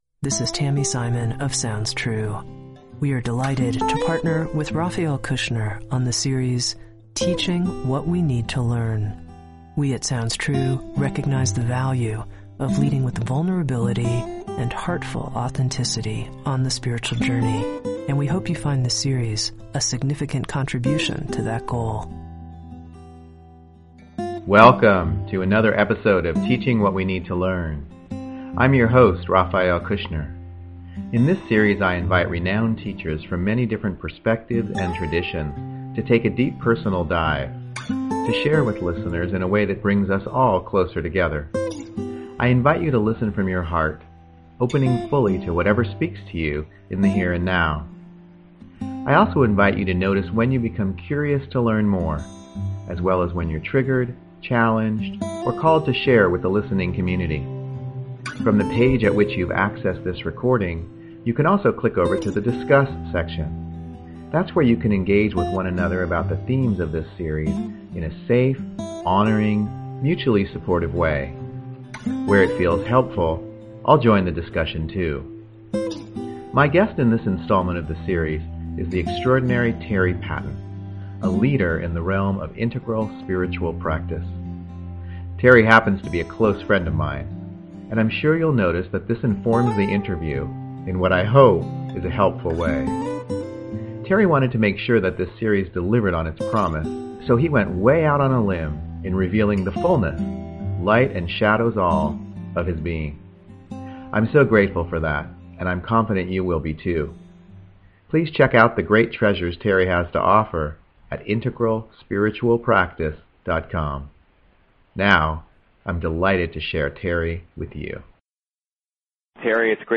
I engaged in a probing, tender, and, well…rather wild dialogue